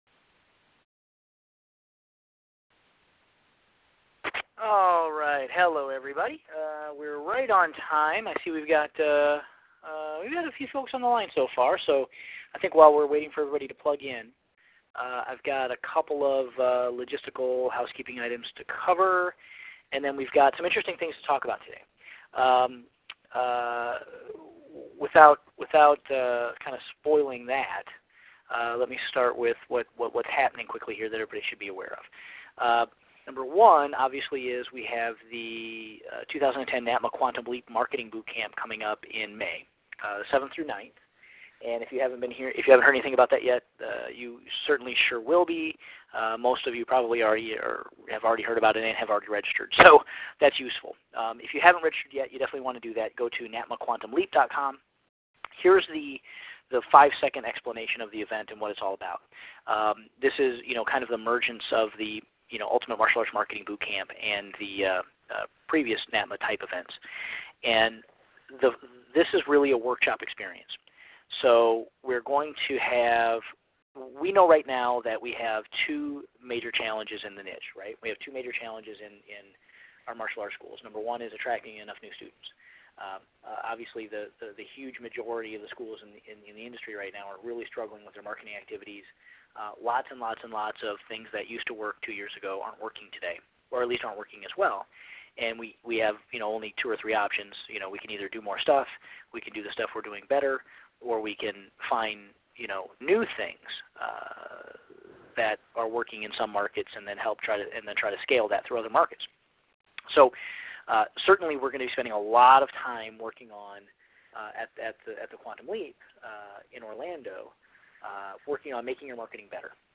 Category: PP-IC - Teleconferences